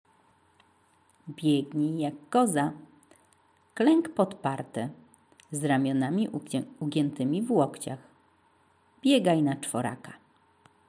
koza
koza.mp3